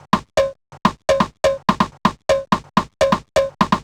cch_percussion_loop_funker_125.wav